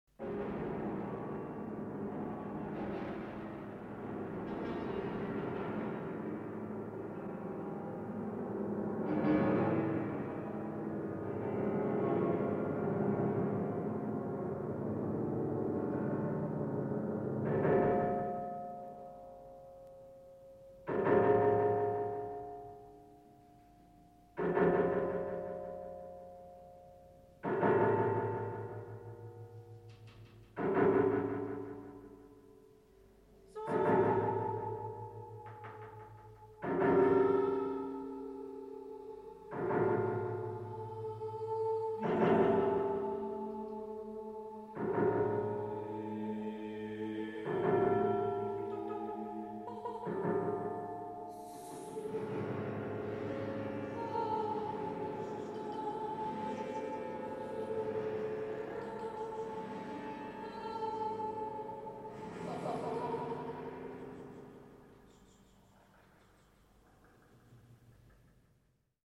nastro magnetico
audio 44kz stereo